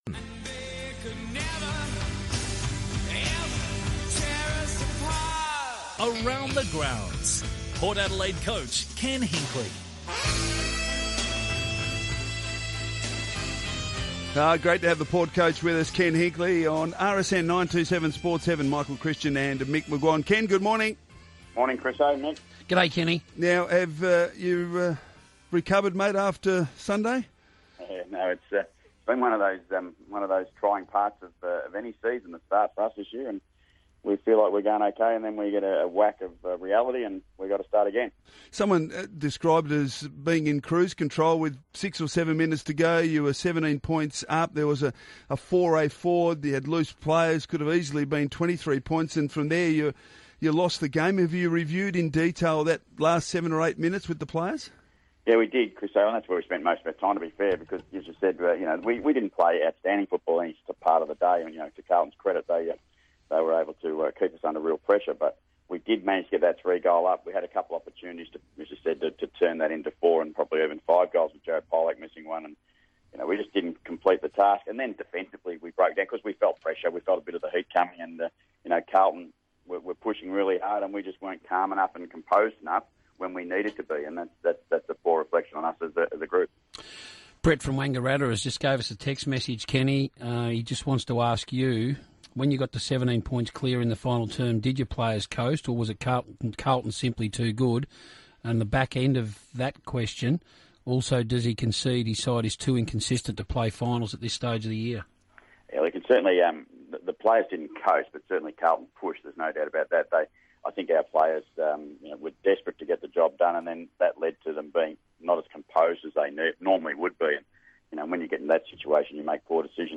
Ken Hinkley on RSN - Thursday, 19 May, 2016
Ken Hinkley talks to Michael Christian and Mick McGuane.